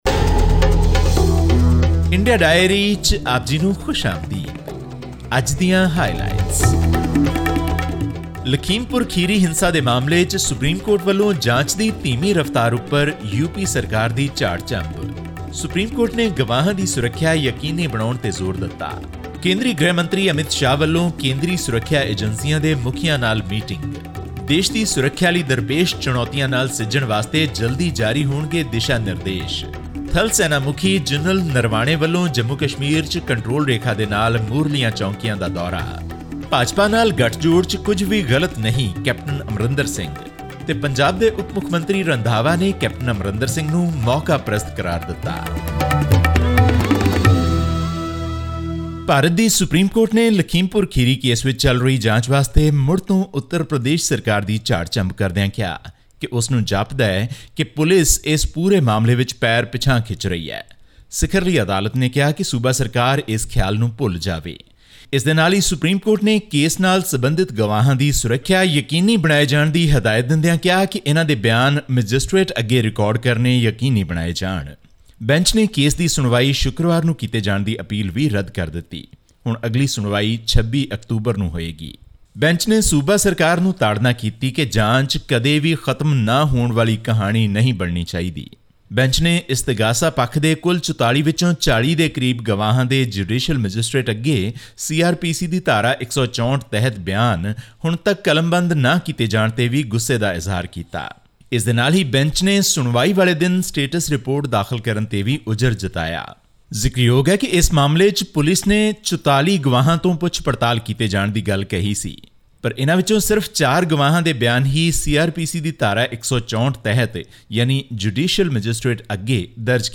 All this and more in our weekly news segment from India.